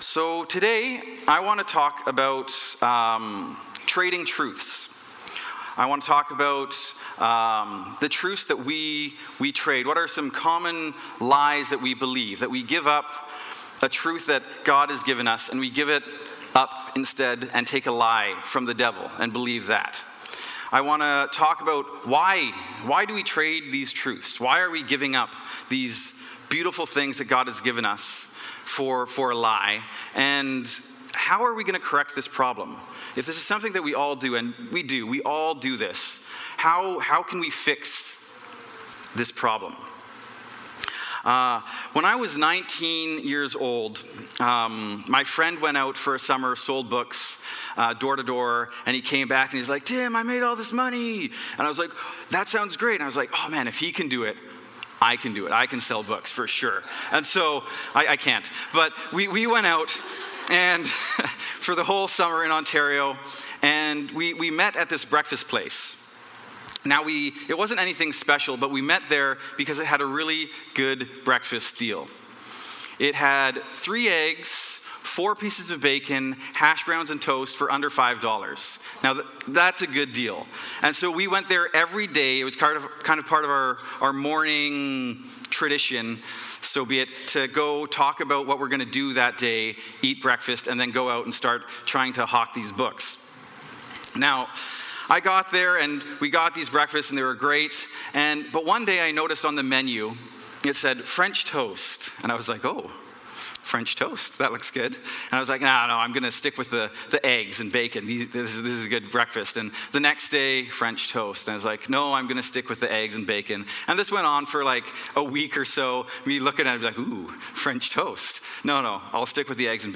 Sermons | Grace Church